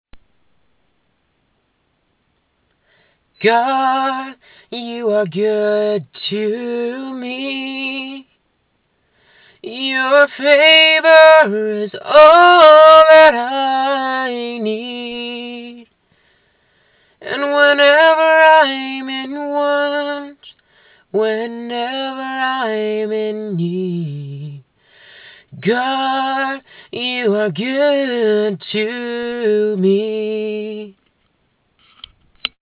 I was actually able to find the original idea I had recorded on my phone the night I wrote it.